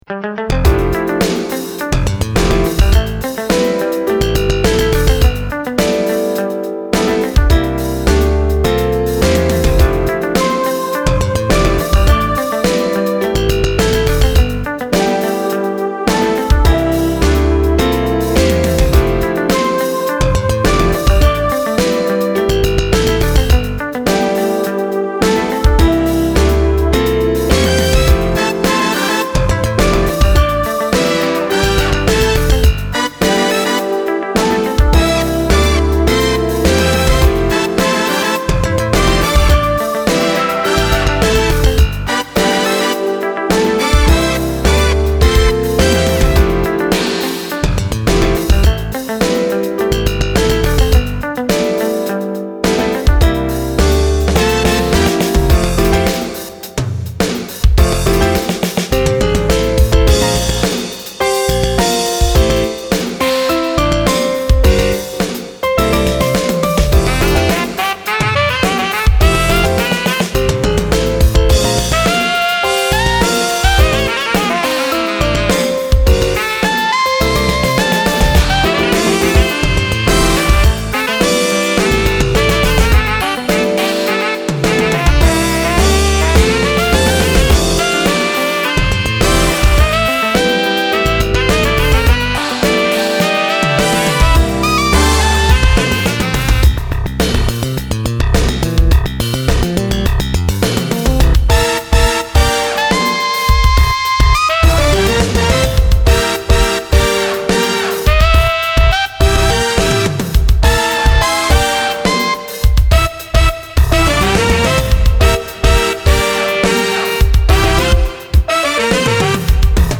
3曲目と同様にサックスのアドリブにはまっていた頃の作品。
エンディングは、対照的にシティーっぽい雰囲気で。